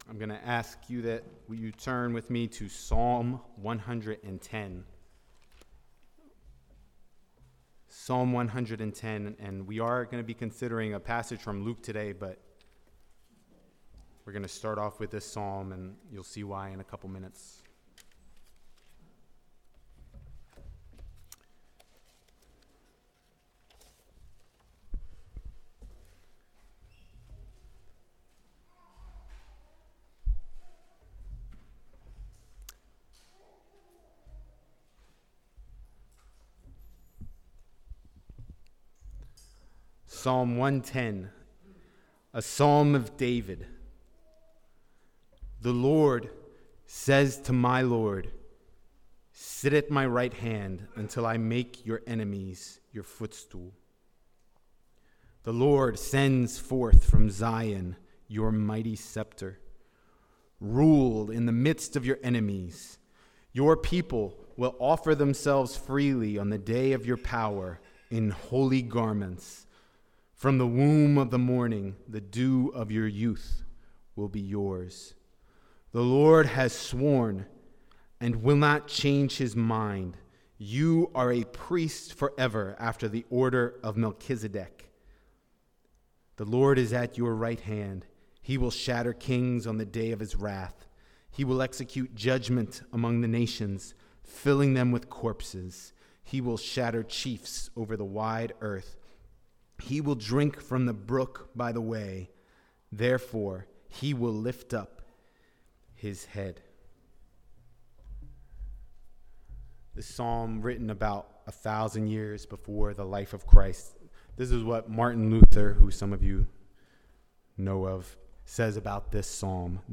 Luke Passage: Luke 20:41–47 Service Type: Sunday Morning « Heaven Is Better Than You Think Children’s Sermon